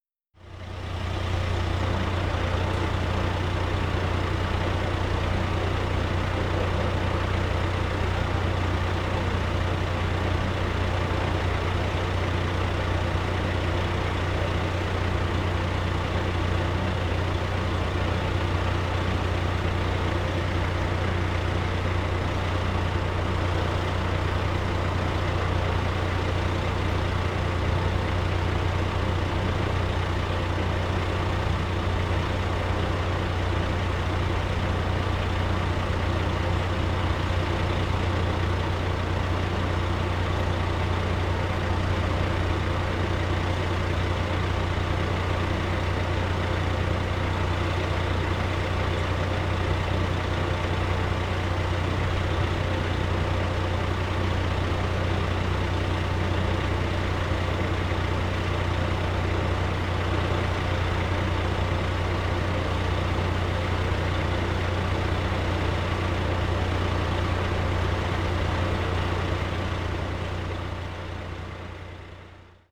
Truck Engine Idle Sound
transport
Truck Engine Idle